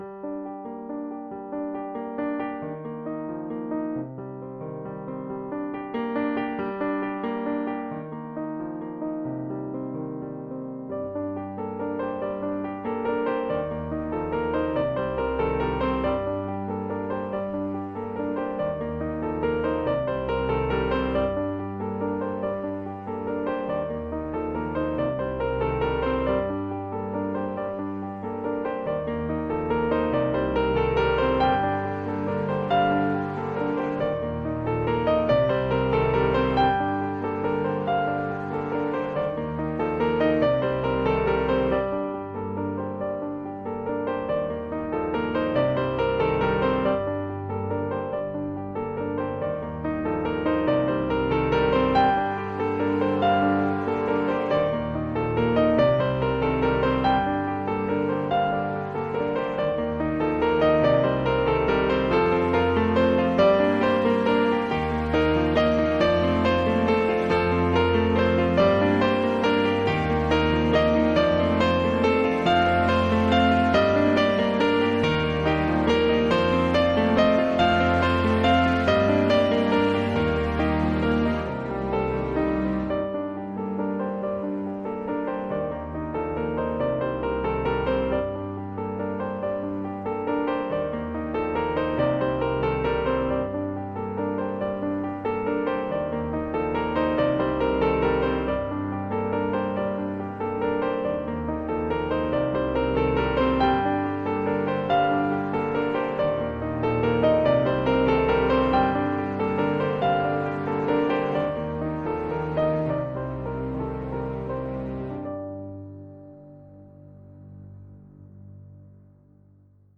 Instrumental, piano, strings. 1.0 MB.